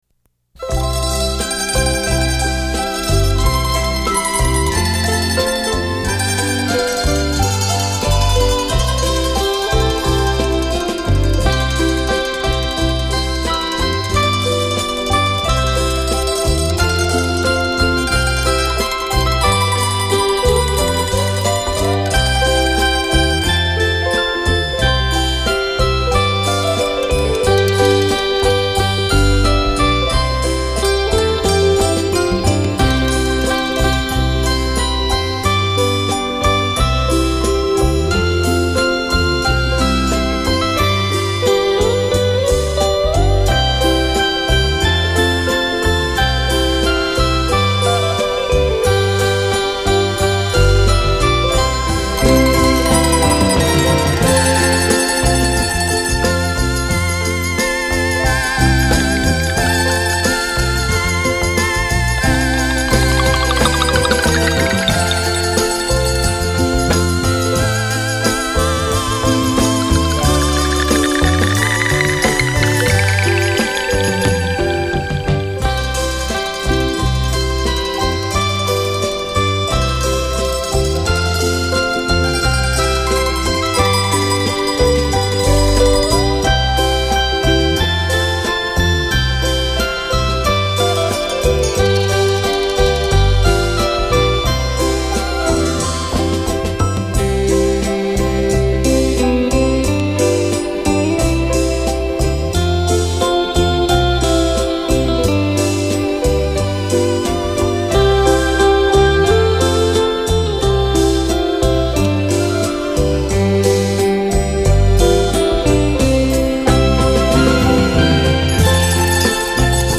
44.100 Hz;16 Bit;立体声